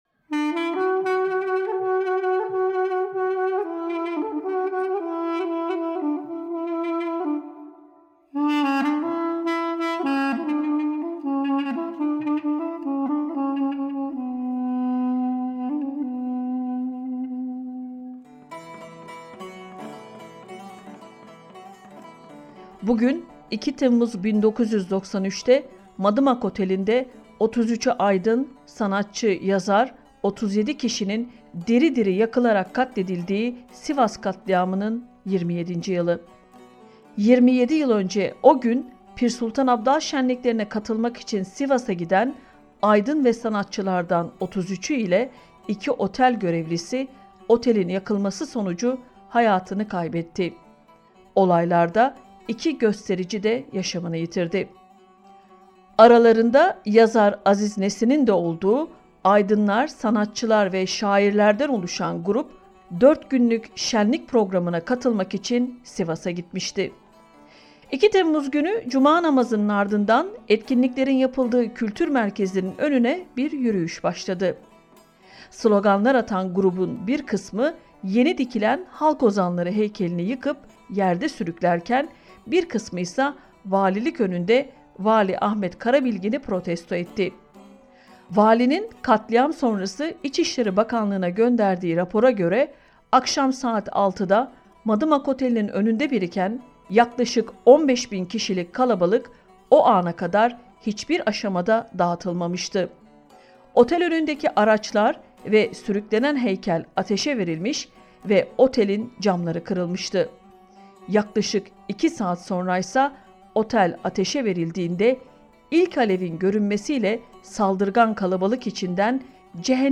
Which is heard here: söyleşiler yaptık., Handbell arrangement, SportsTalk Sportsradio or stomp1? söyleşiler yaptık.